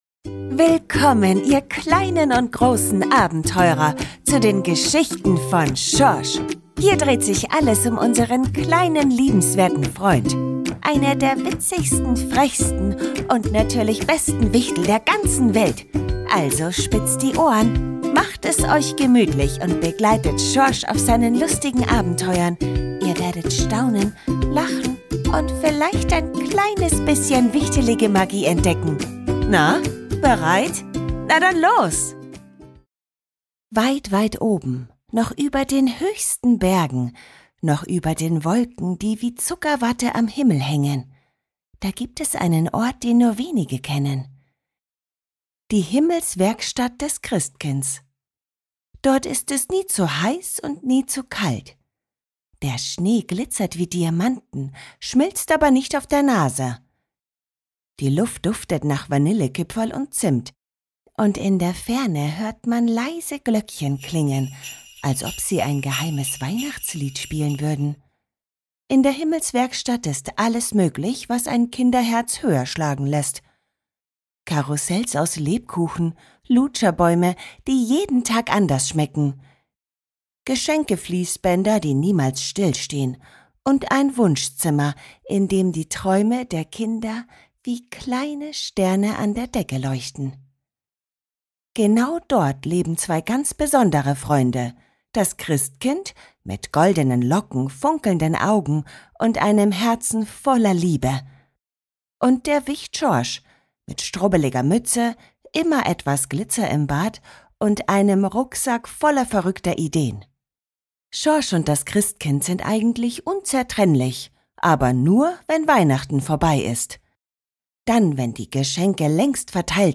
Sprecherin für Hörbücher, Werbung, VoiceOver, Erklärvideos, Imagefilme, Audiobooks uvm.